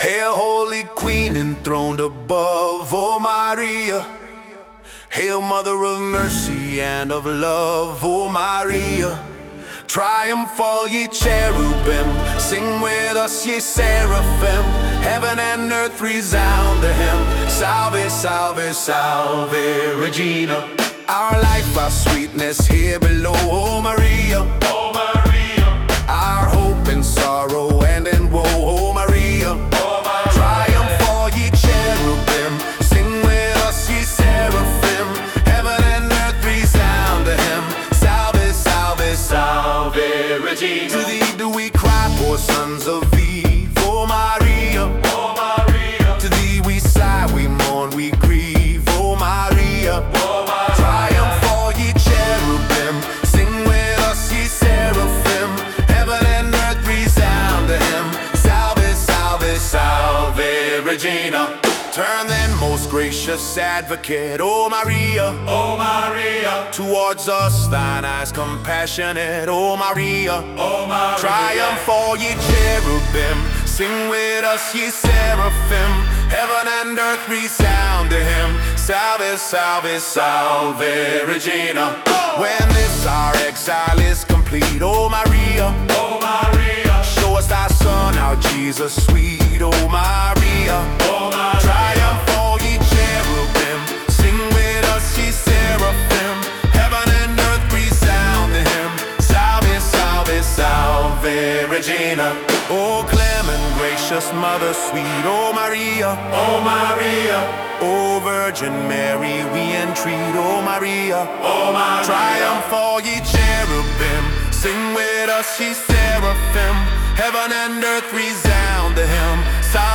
Rap version